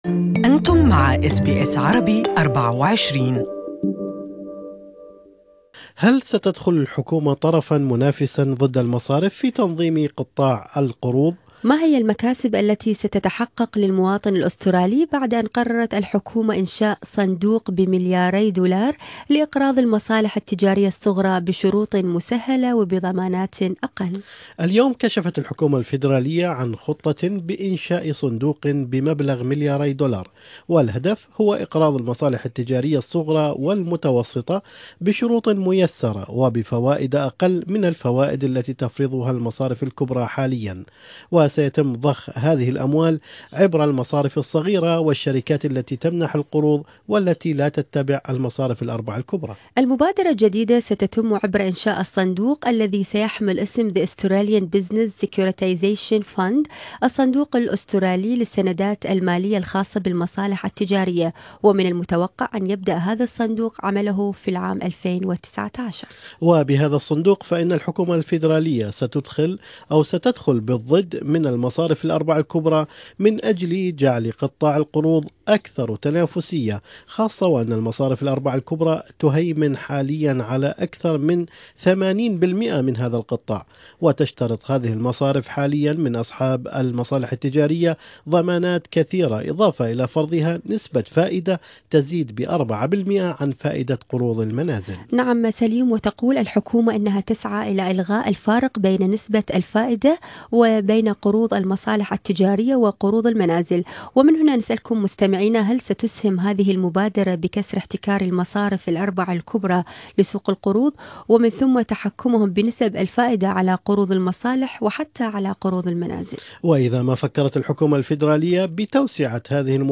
وللتعليق على هذا الموضوع كان لنا هذا اللقاء مع الاستشاري في مجال الاعمال والمصالح التجارية